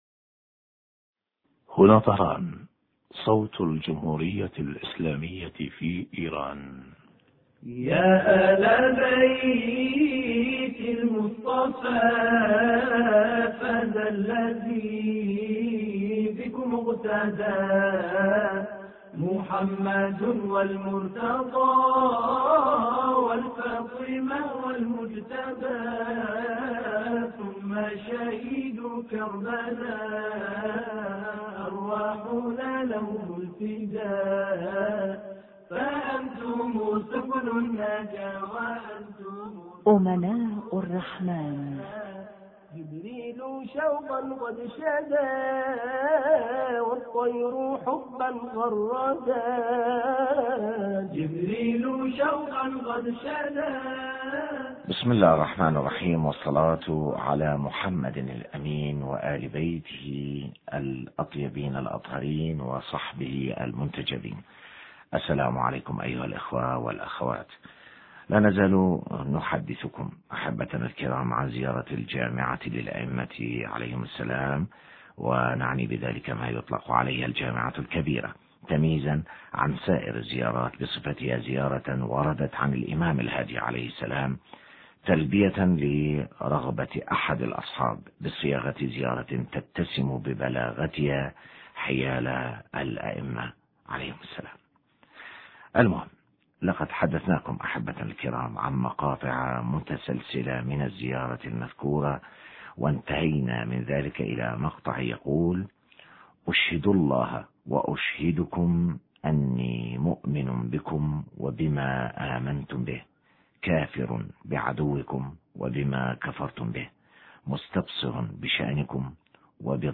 بيان معنى قول الزائر أشهد الله وأشهدكم إني بكم مؤمن حوار